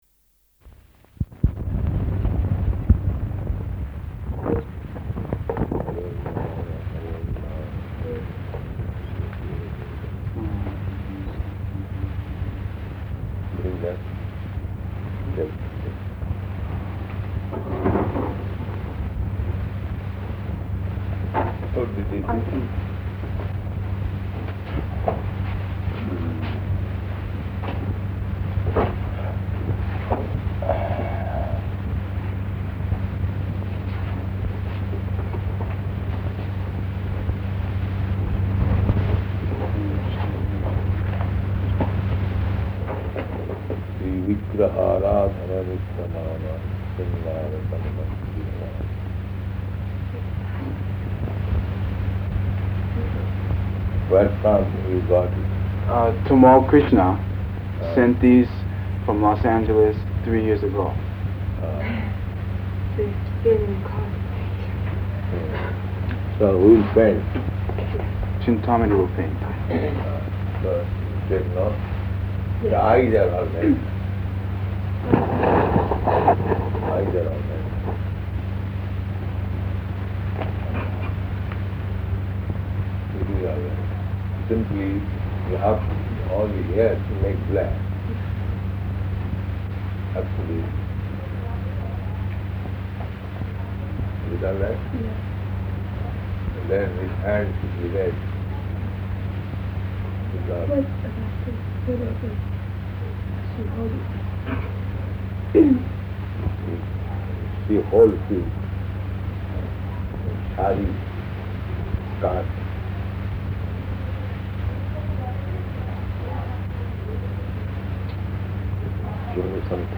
Room Conversation
Room Conversation --:-- --:-- Type: Conversation Dated: April 22nd 1972 Location: Tokyo Audio file: 720422R1.TOK.mp3 Prabhupāda: harer nāma harer nāma harer nāma eva kevalam [ Cc.